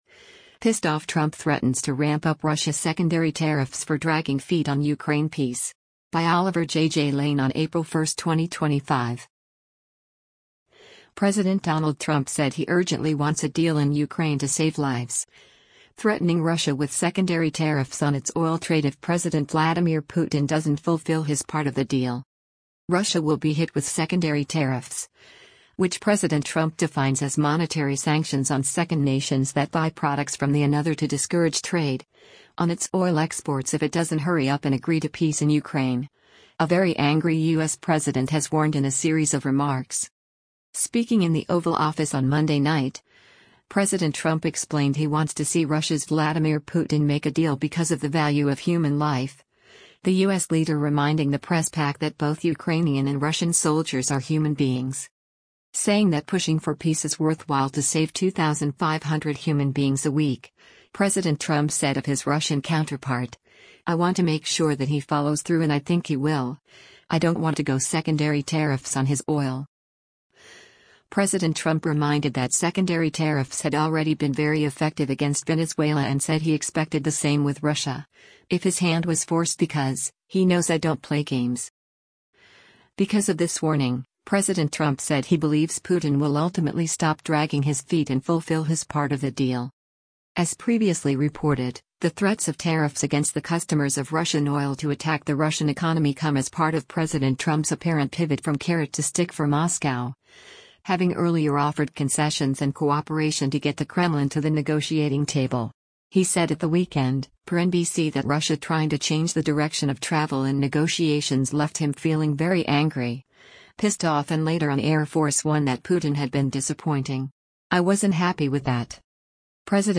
Speaking in the Oval Office on Monday night, President Trump explained he wants to see Russia’s Vladimir Putin make a deal because of the value of human life, the U.S. leader reminding the press pack that both Ukrainian and Russian soldiers are human beings.